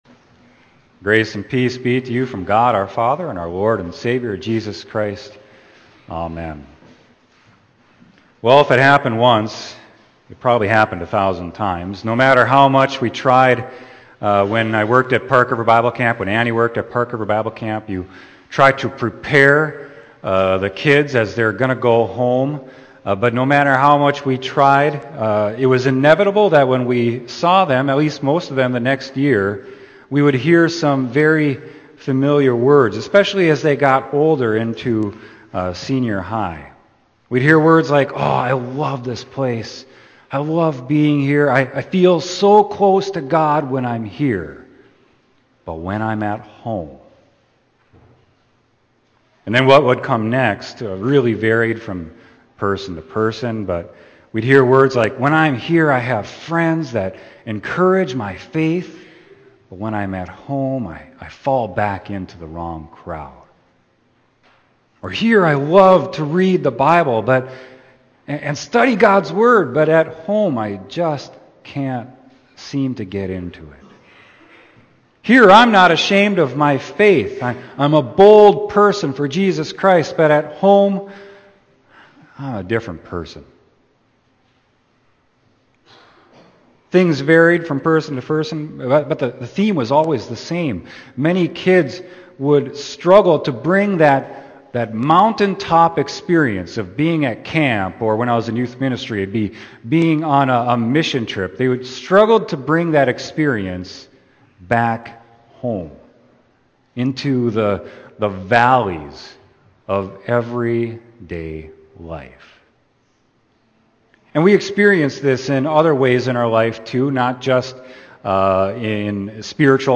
Sermon: Matthew 17.1-9